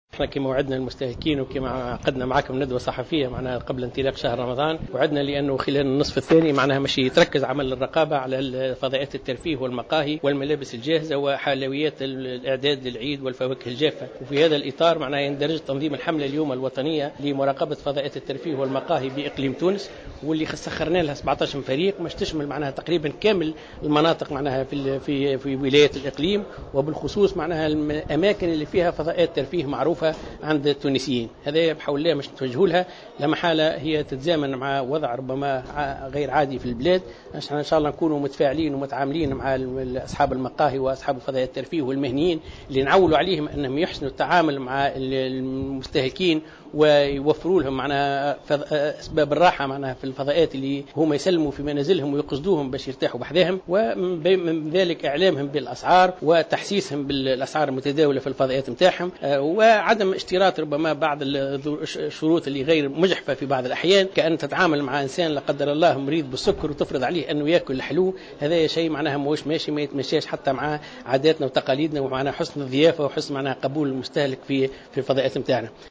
مدير عام المراقبة الإقتصادية محمد عيفة في تصريح